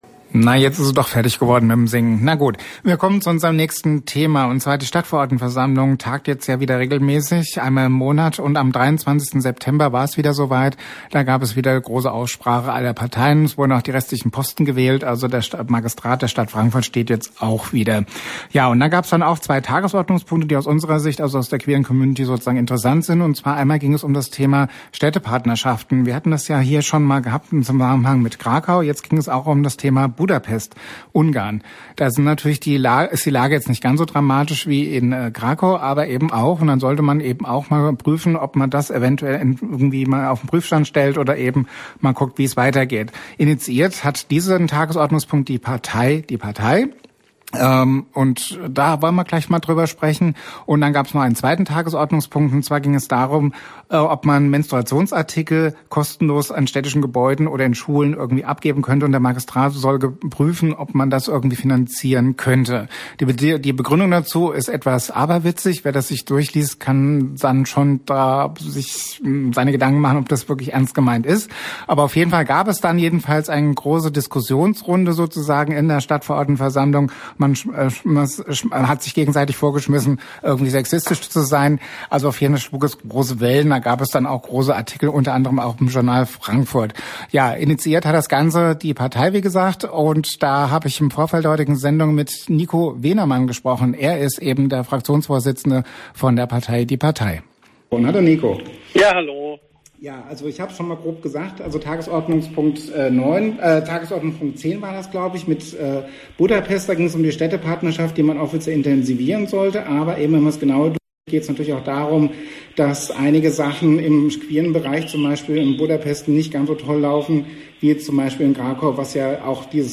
Interview mit Nico Wehnemann, Die Partei